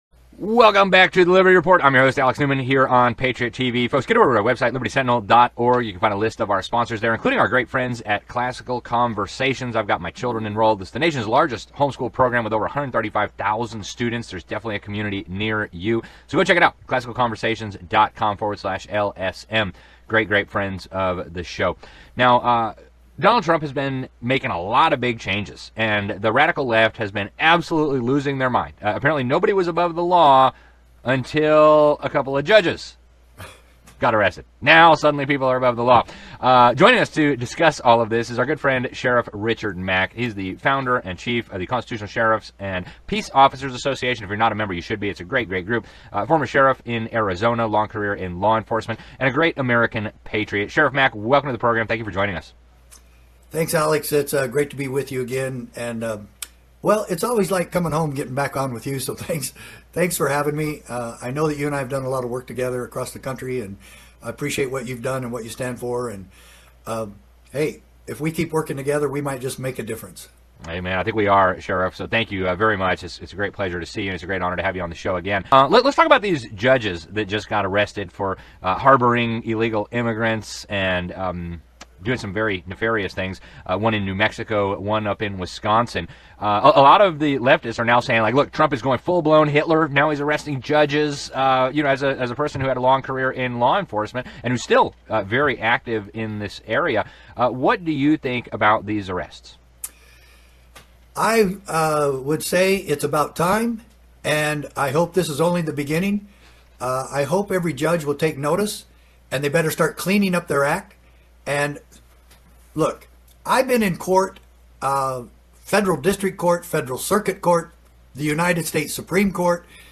He interviews Sheriff Richard Mack, founder of the Constitutional Sheriffs and Peace Officers Association, about the recent arrests of judges for harboring illegal immigrants.